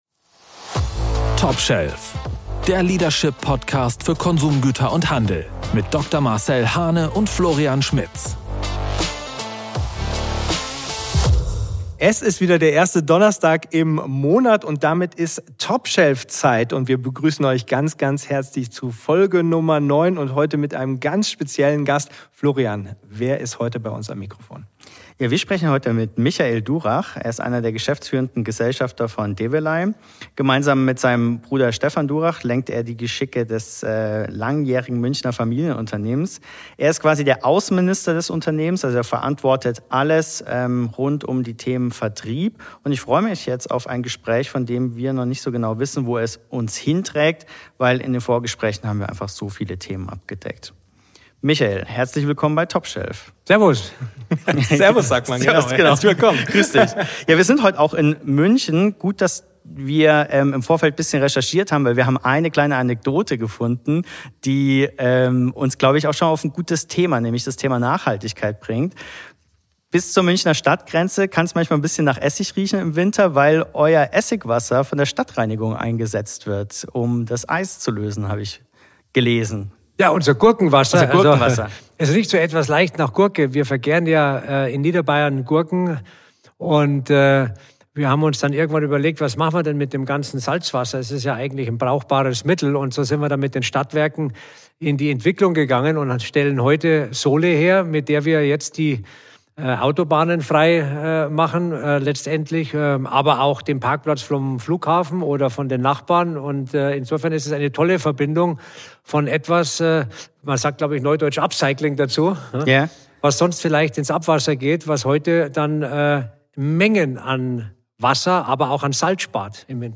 Ein Gespräch über Entscheidungsstärke, Partnerschaft mit dem Handel und Führung ohne politische Spielchen.